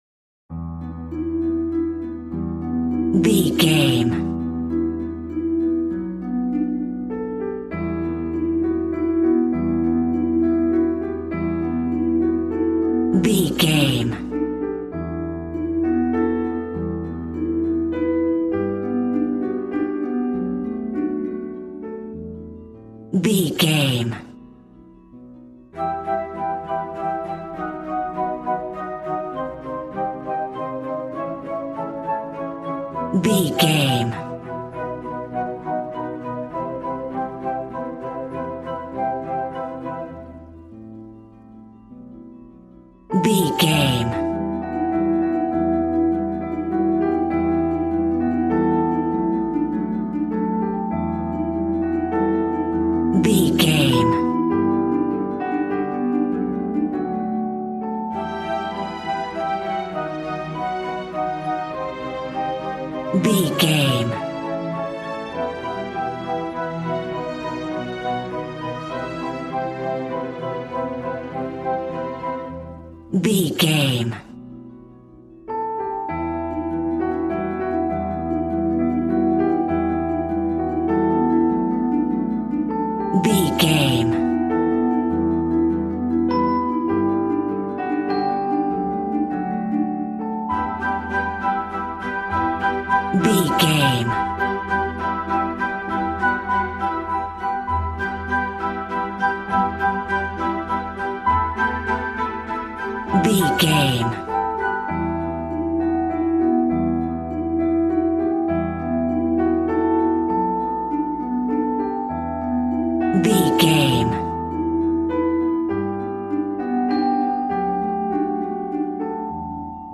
Regal and romantic, a classy piece of classical music.
Aeolian/Minor
regal
strings
violin
brass